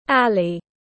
Alley /ˈæl.i/